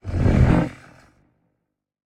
Minecraft Version Minecraft Version 25w18a Latest Release | Latest Snapshot 25w18a / assets / minecraft / sounds / mob / warden / listening_4.ogg Compare With Compare With Latest Release | Latest Snapshot
listening_4.ogg